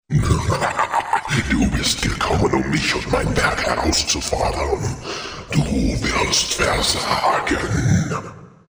Voice-Over Recordings
picture x Ork:
"Exemplary voice-overs."